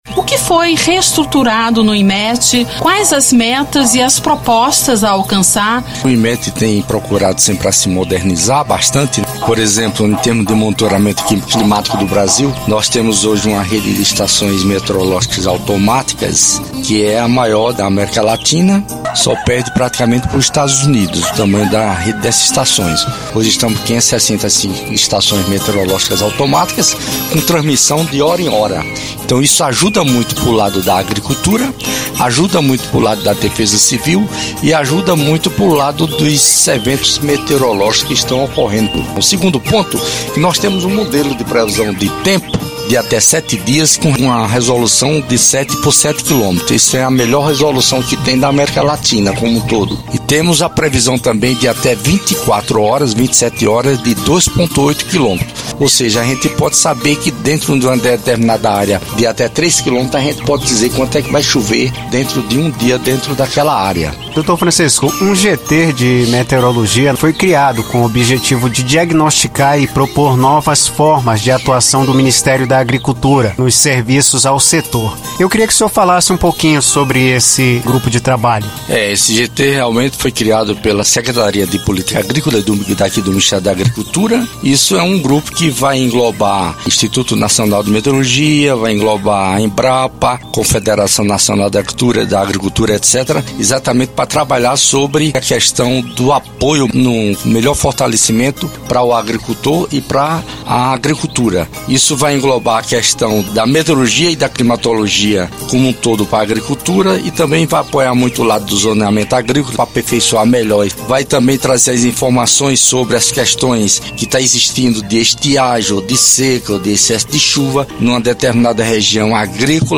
E aconteceu um Dedo de Prosa no Ministério da Agricultura Pecuária e Abastecimento com o diretor do Instituto Nacional de Meteorologia (INMET), Francisco de Assis, sobre novas metas para o instituto.